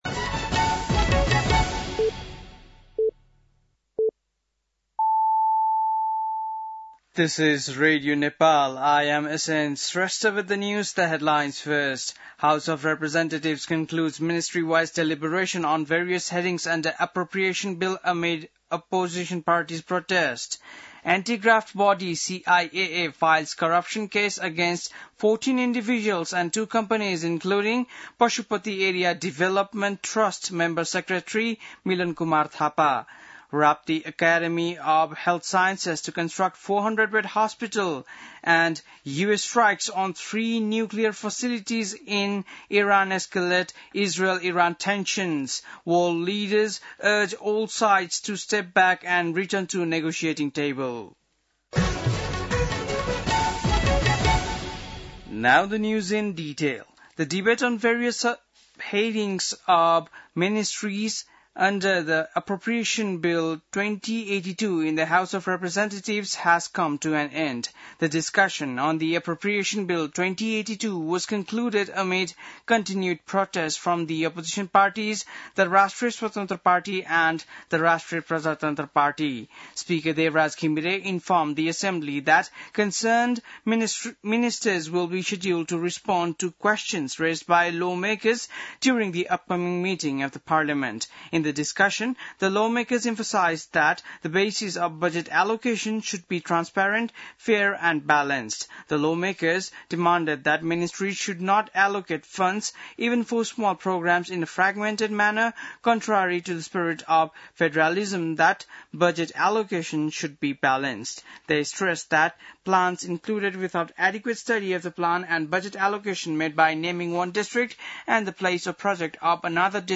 बेलुकी ८ बजेको अङ्ग्रेजी समाचार : ८ असार , २०८२
8-pm-english-news-3-08.mp3